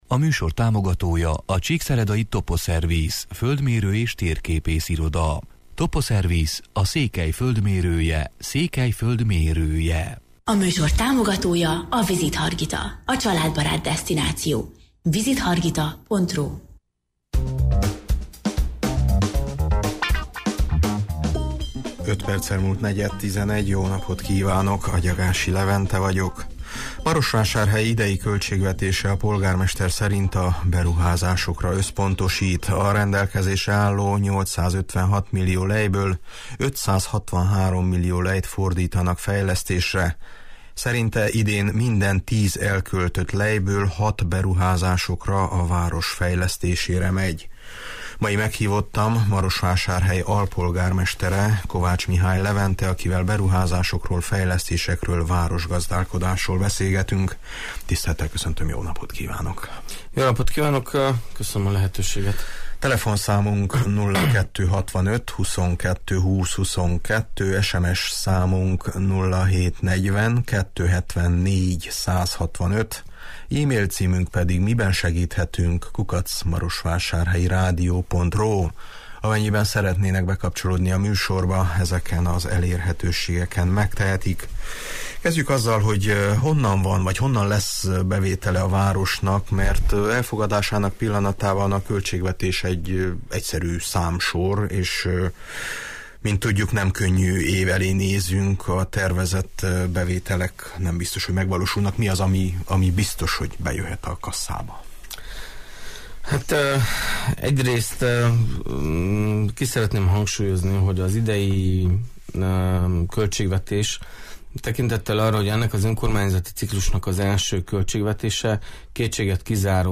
Mai meghívottam Marosvásárhely alpolgármestere, Kovács Levente, akivel beruházásokról, fejlesztésekről, városgazdálkodásról beszélgetünk: